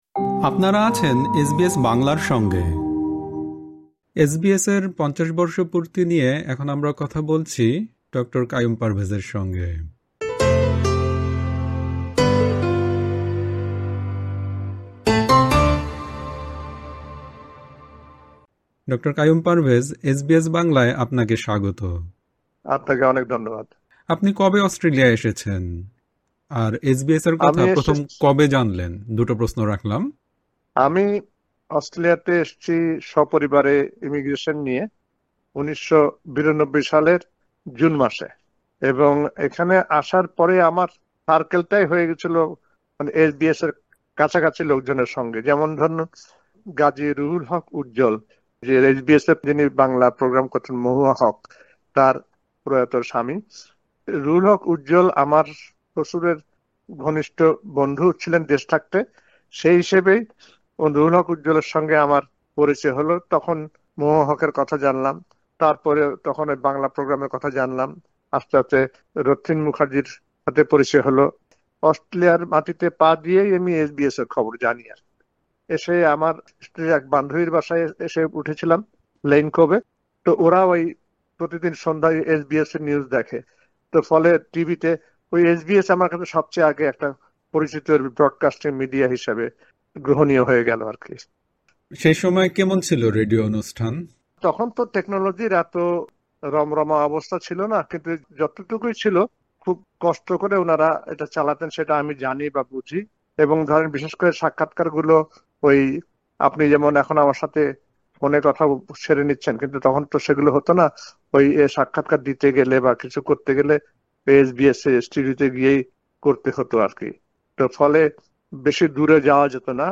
এসবিএস-এর ৫০ বর্ষপূর্তি নিয়ে এসবিএস বাংলার সঙ্গে কথা বলেছেন